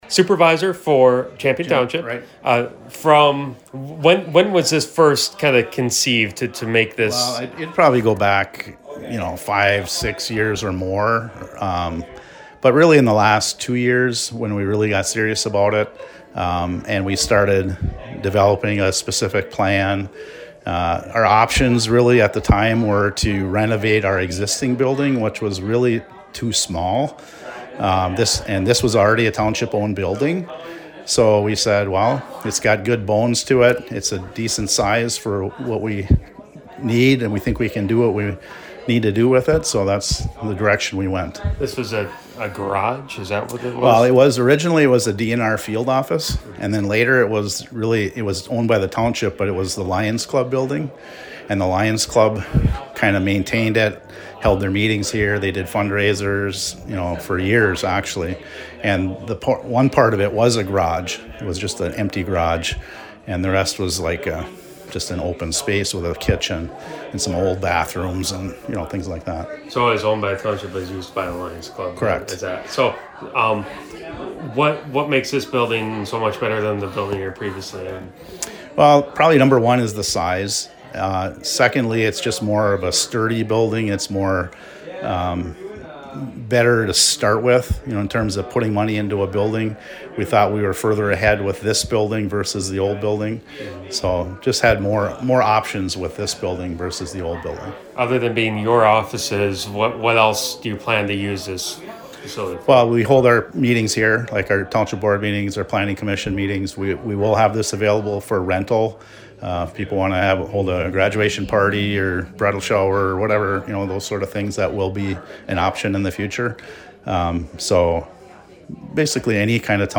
INTERVIEW WITH SUPERVISOR MIKE SIMULA